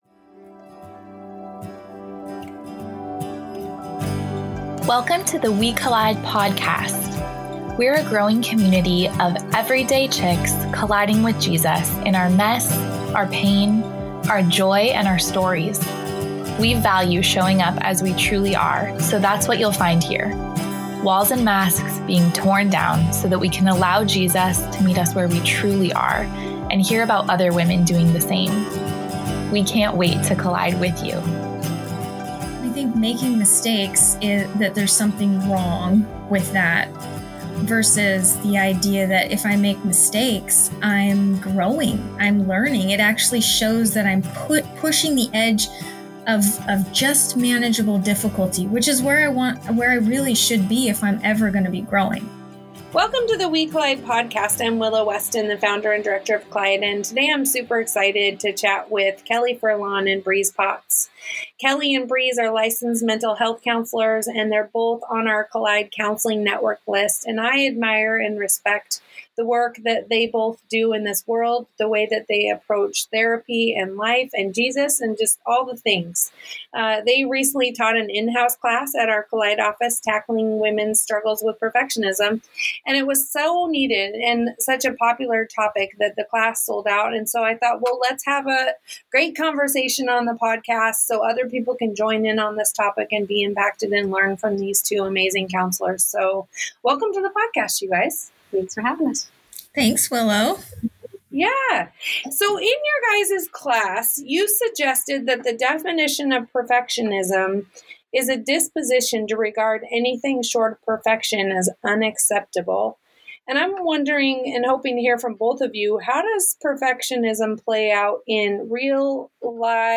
This conversation is an invitation to release the pressure to perform and discover a healthier, more grace-filled way forward.